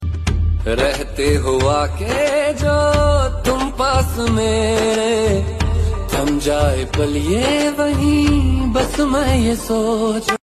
soothing and romantic ringtone